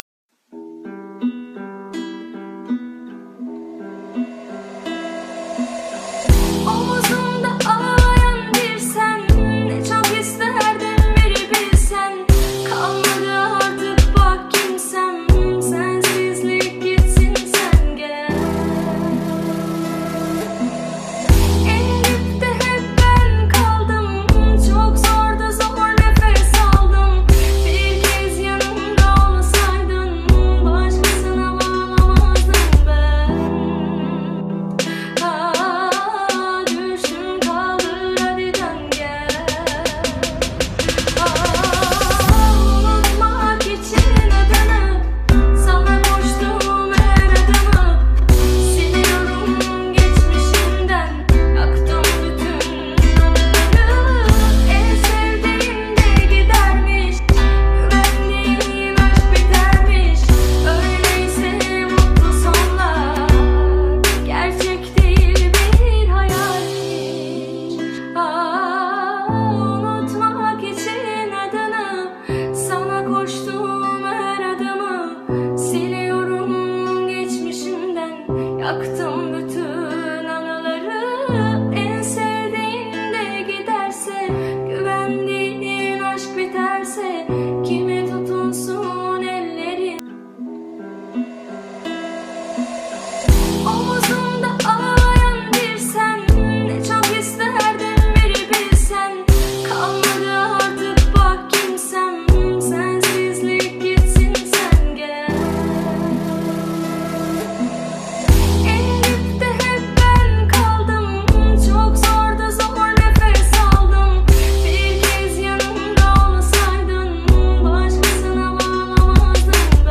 это проникновенная песня в жанре турецкой народной музыки
выразительным вокалом и эмоциональной интерпретацией